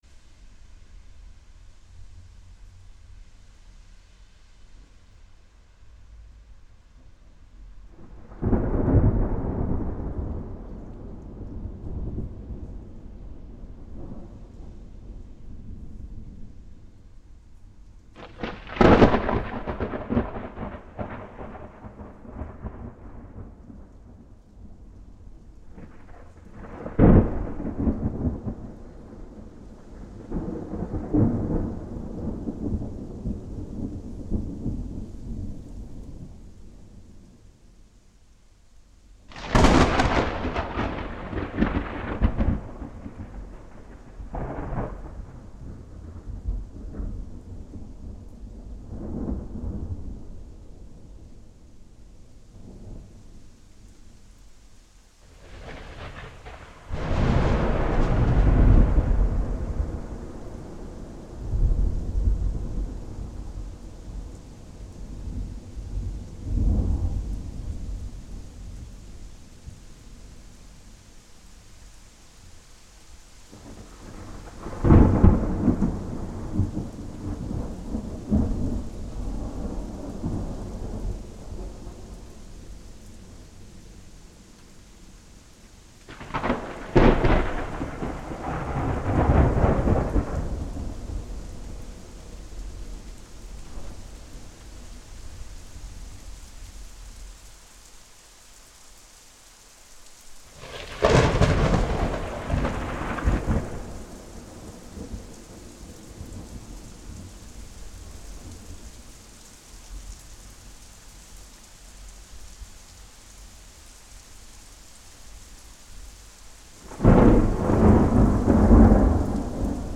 thunderstorm2
Category 🌿 Nature
explosion field-recording flash growl horror lightning low nature sound effect free sound royalty free Nature